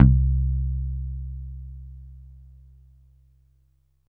14 BASS C3.wav